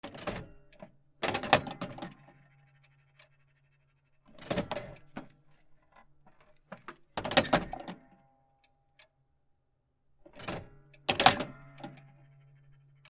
公衆電話 受話器